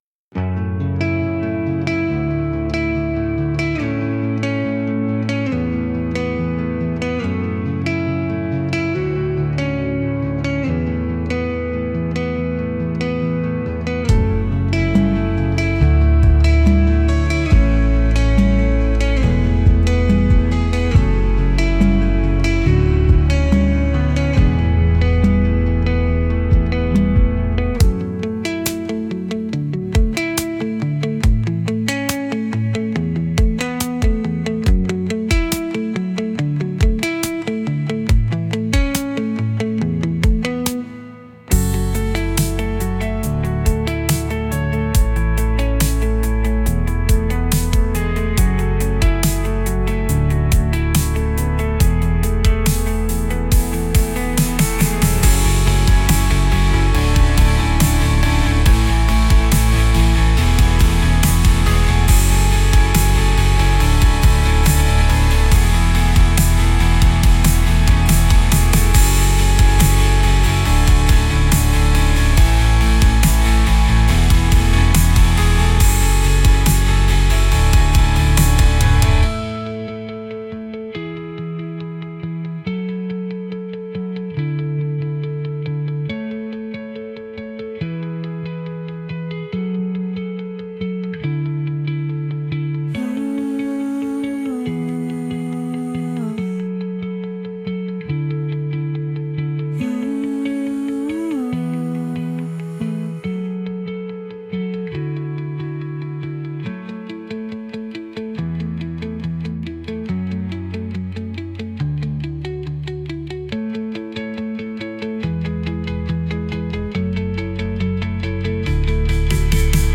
Genre: Melancholic Mood: Heartfelt Editor's Choice